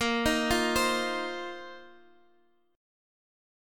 Listen to A#sus2sus4 strummed